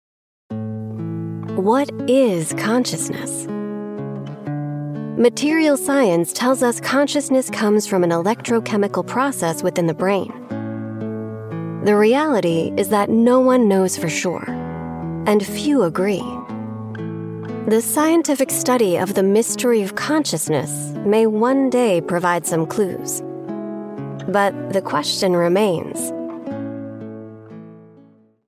Anglais (Américain)
Commerciale, Jeune, Chaude, Naturelle, Amicale
Guide audio